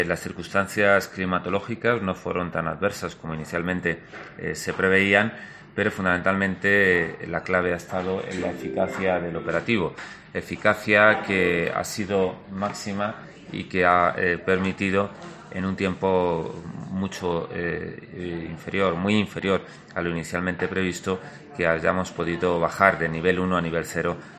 Delegado territorial. Incendio Poyales del Hoyo